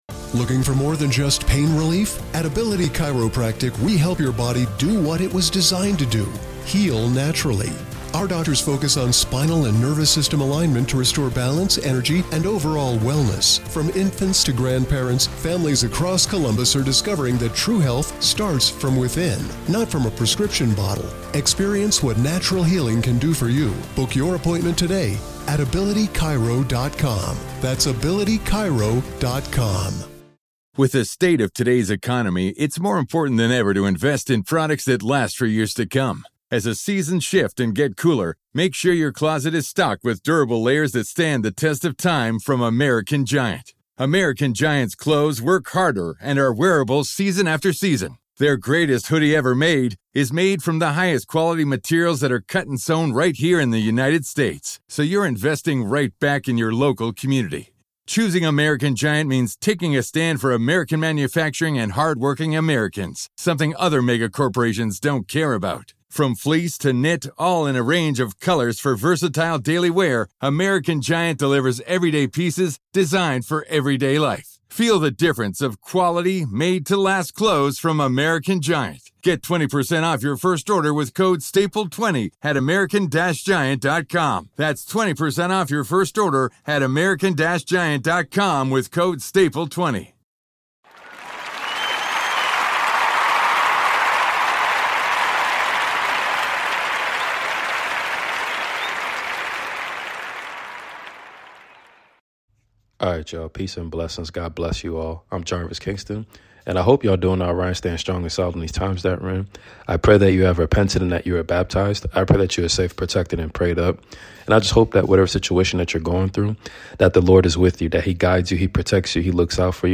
Book of Matthew Chapters 9-10 reading ! Healing & Forgiveness is important in our lives!